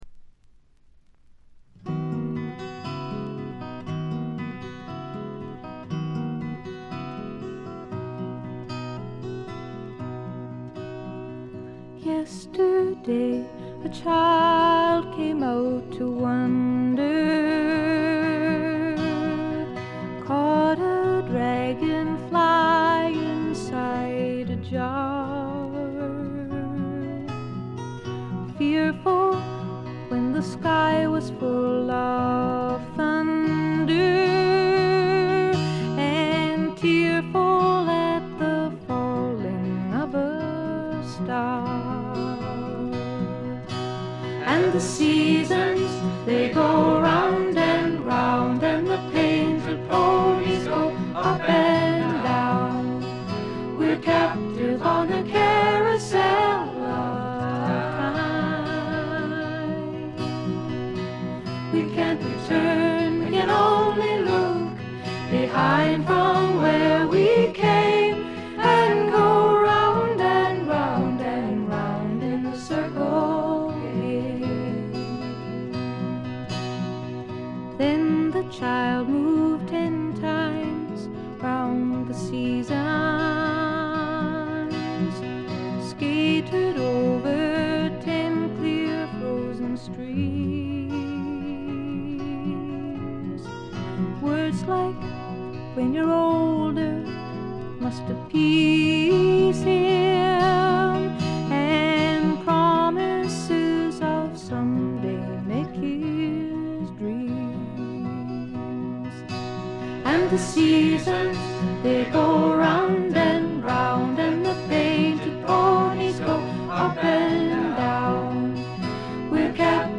ところどころで軽微なチリプチ。
美しいことこの上ない女性シンガー・ソングライター名作。
試聴曲は現品からの取り込み音源です。